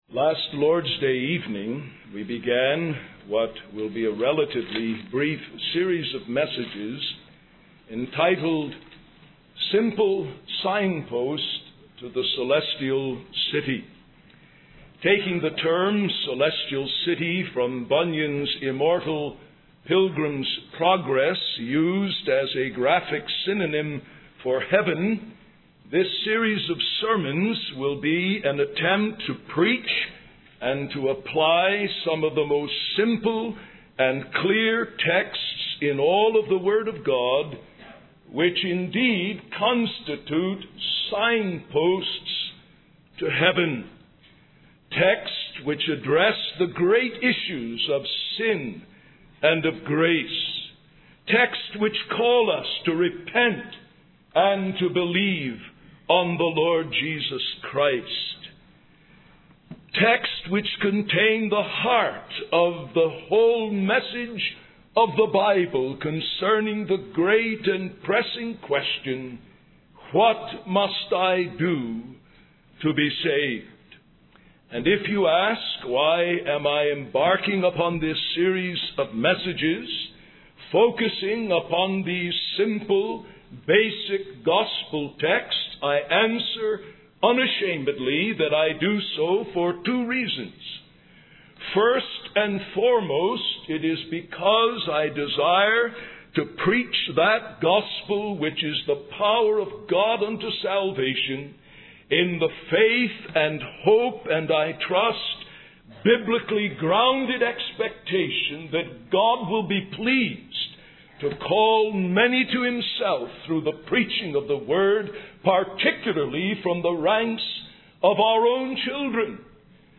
In this sermon, the speaker begins by addressing the audience, particularly the children and young people, emphasizing the importance of following the directions of the signpost to the celestial city.